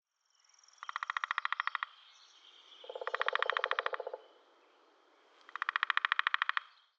Les sons vont au-delà des simples cris des animaux. Ils restituent l’univers de ce moment de la journée, à la campagne.
08-Le-pic-epeiche_Main_V2.wav